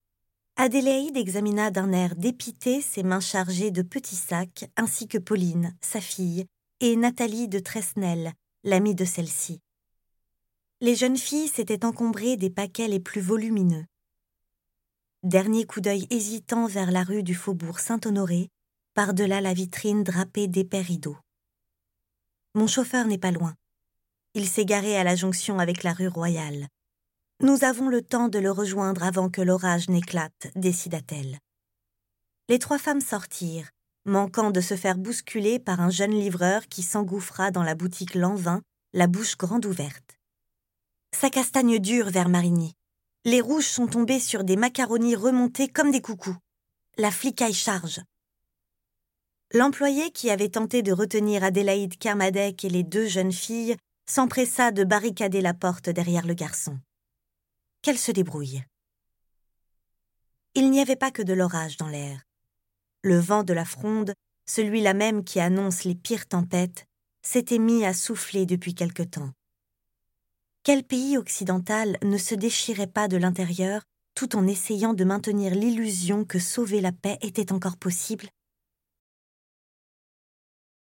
My voice is young, dynamic, sensual, soft, luminous and smiling… Just like me !
AUDIOBOOK